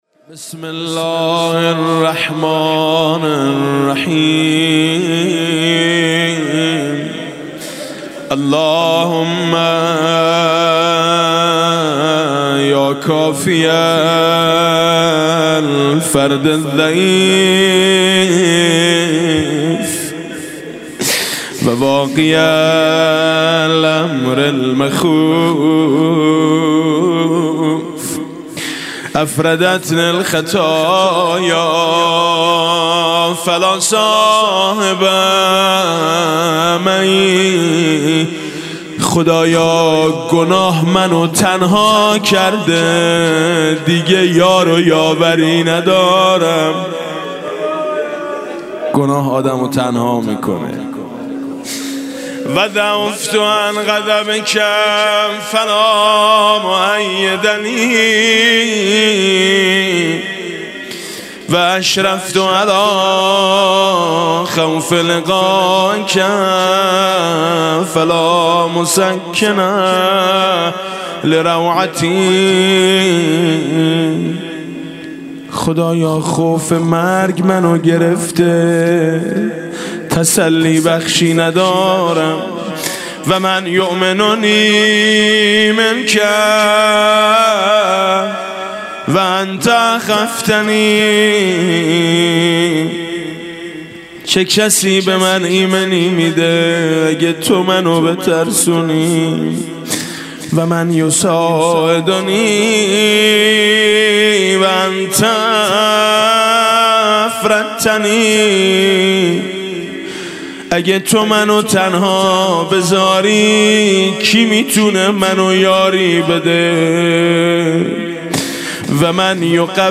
مناسبت : شب هشتم رمضان
مداح : میثم مطیعی قالب : مناجات